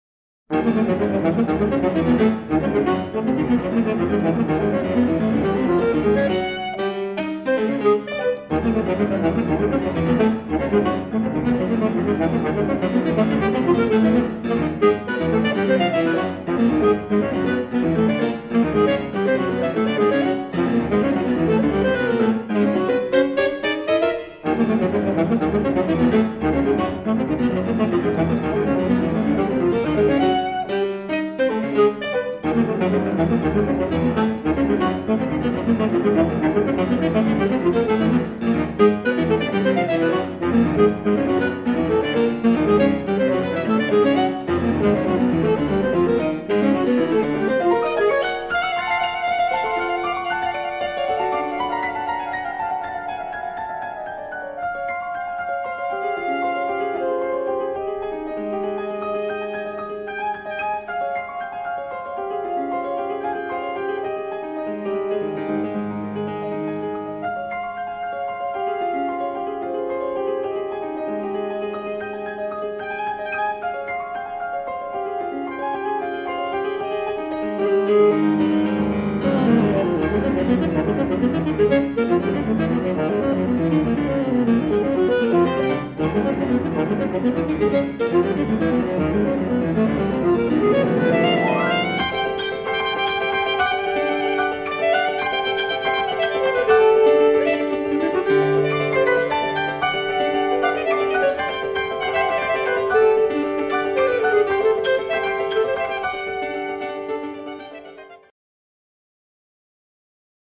Instrumentation: E-flat alto saxophone and piano